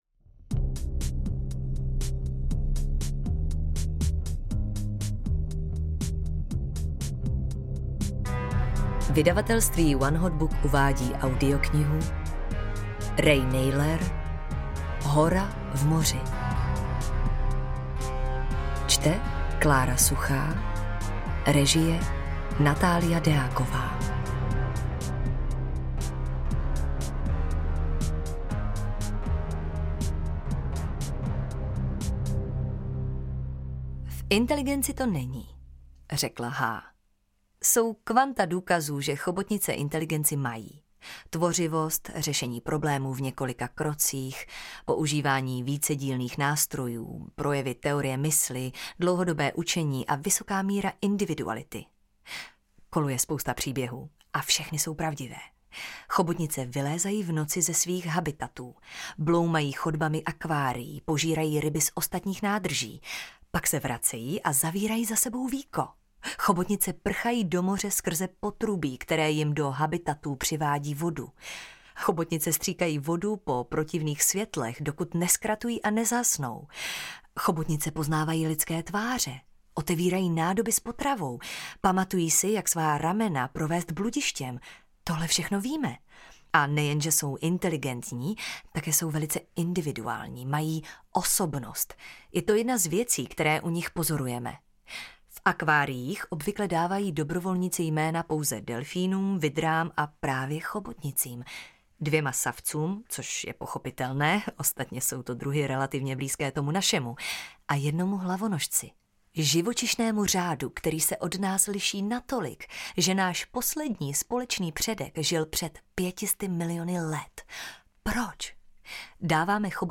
Hora v moři audiokniha
Ukázka z knihy